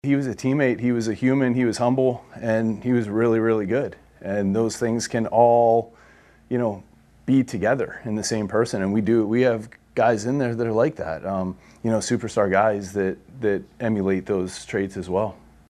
After Maz’s death was announced on Saturday, manager Donnie Kelly saluted the Hall of Famer, saying he sees some of his great qualities in the current Bucs.